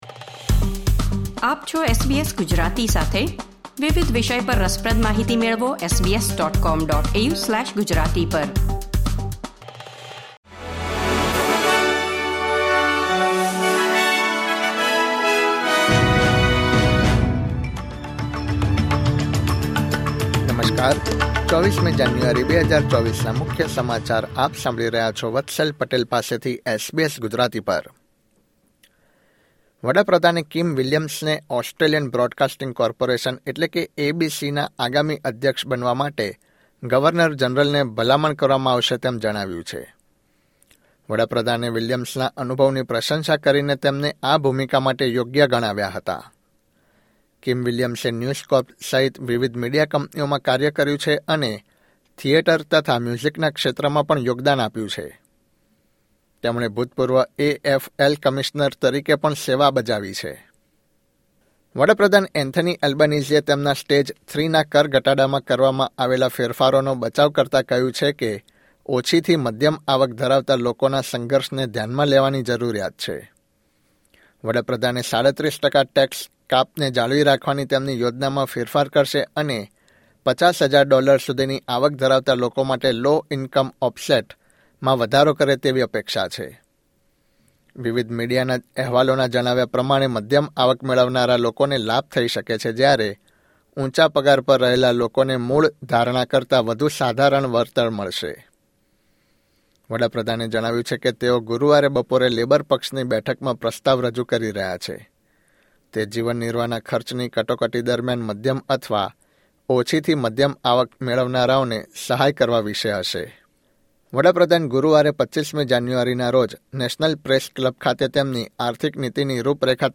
SBS Gujarati News Bulletin 24 January 2024